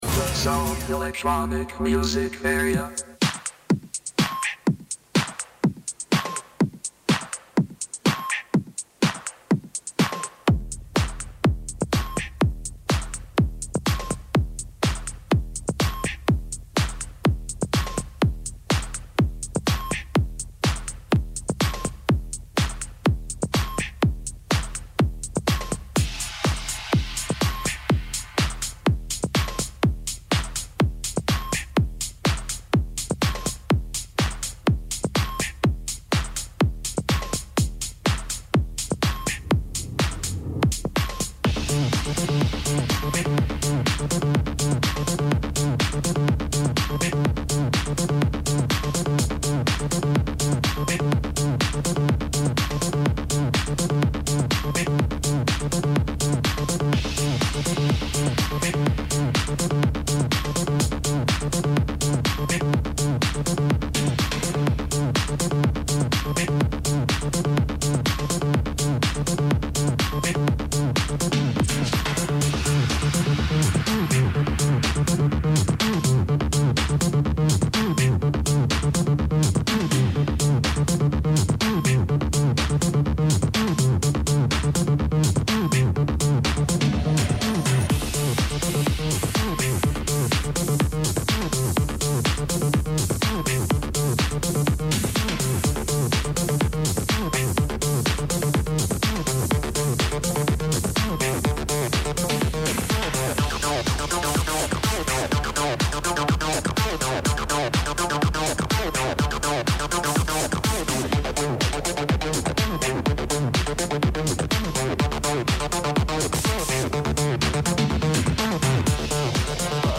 Live show with NEW Promo & Upcoming Releases